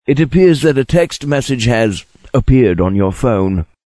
SMS Tone > MessageTones Voice